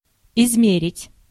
Ääntäminen
IPA : /ˈmɛʒ.əɹ/